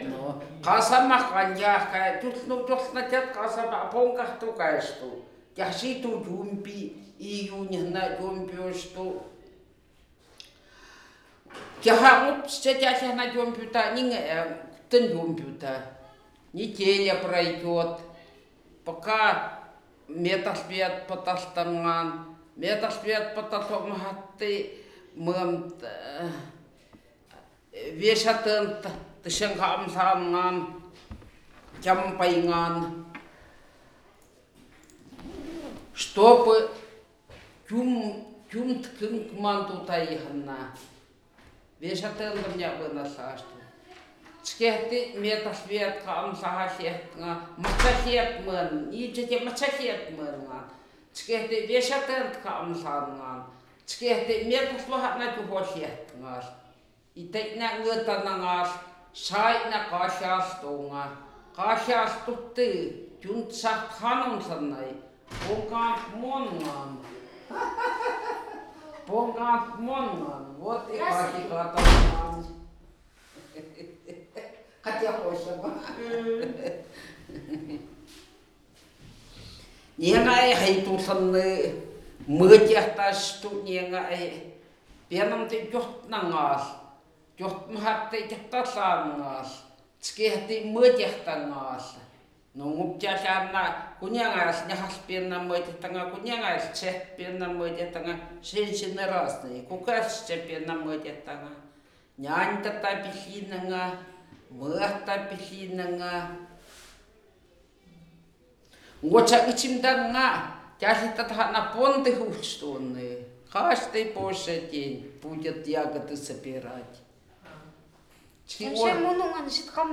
Место записи: д. Харампур